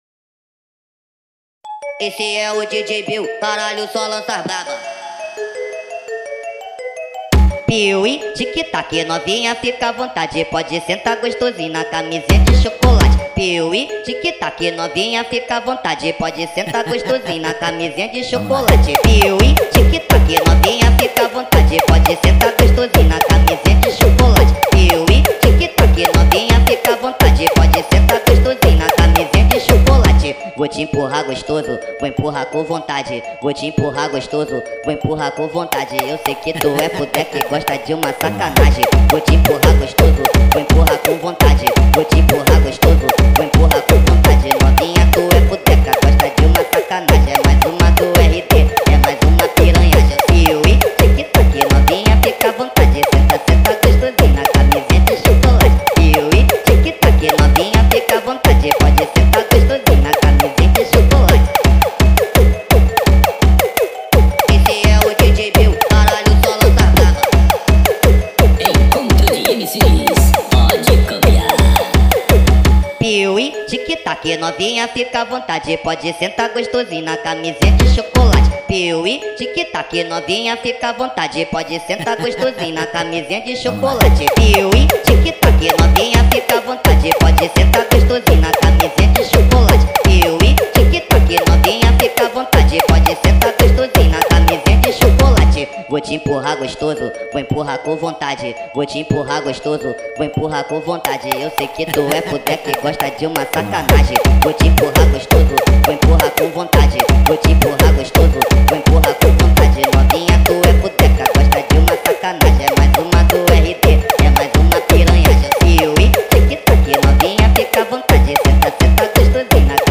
فانک